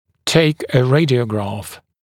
[teɪk ə ‘reɪdɪəgrɑːf][тэйк э ‘рэйдиэгра:ф]сделать рентгеновский снимок